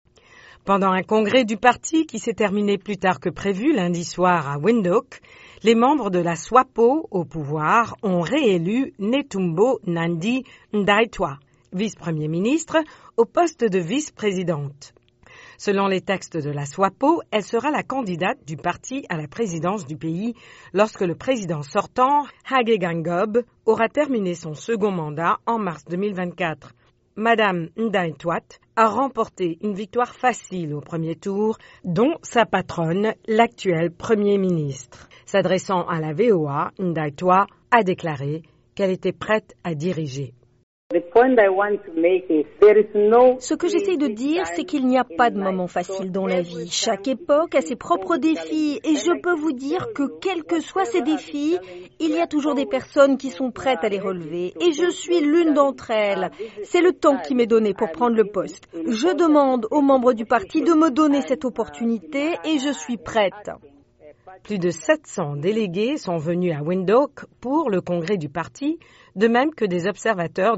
En Namibie le parti au pouvoir a choisi Netumbo Nandi-Ndaitwah comme vice-présidente, ce qui lui permettra d'être la première femme candidate à la présidence du pays lorsque l’actuel président quittera le pouvoir en mars 2024. Reportage